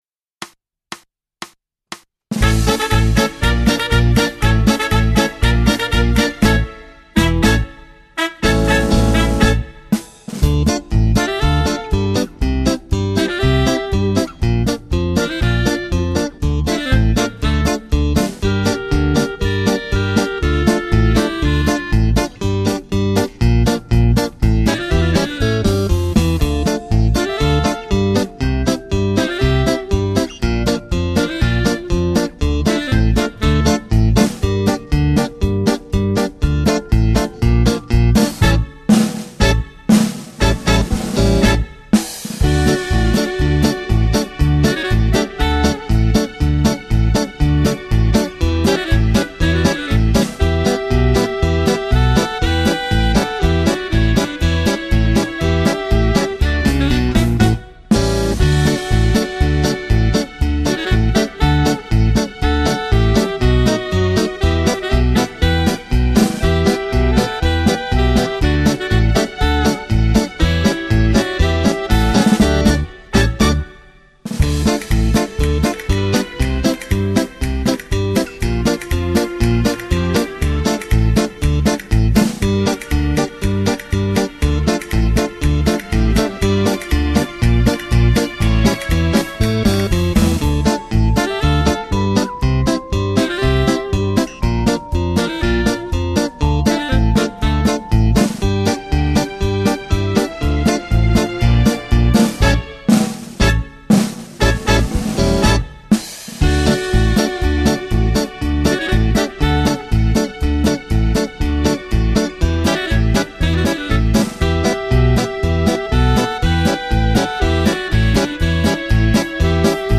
Genere: Paso doble
Scarica la Base Mp3 (2,66 MB)